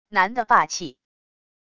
男的霸气wav音频